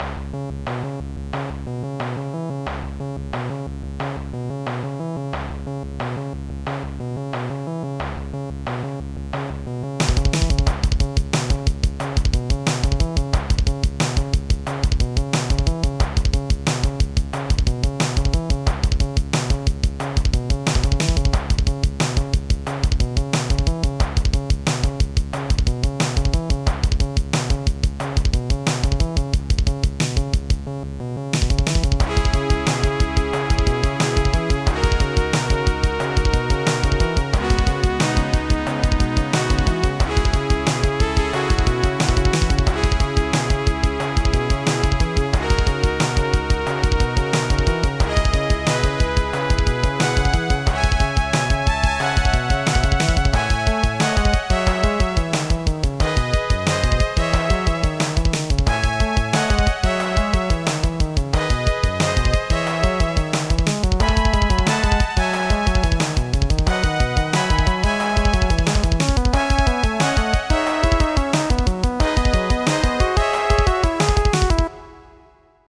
ambient tracks